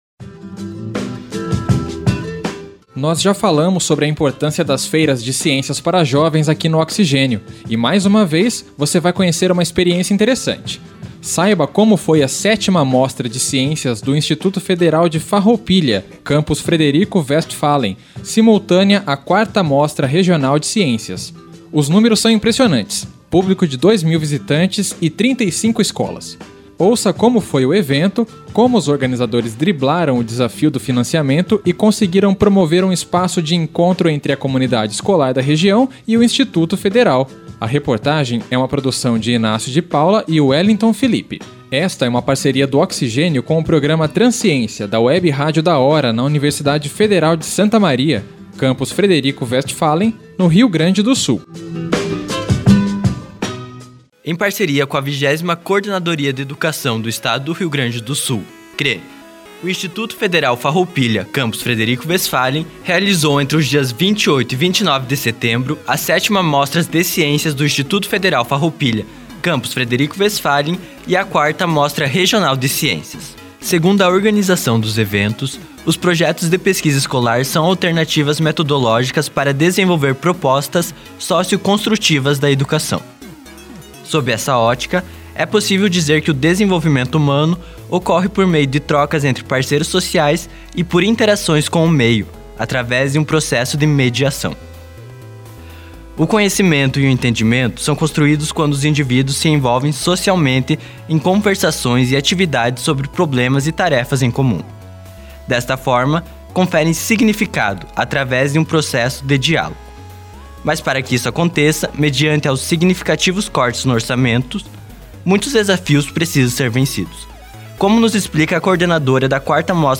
Reportagem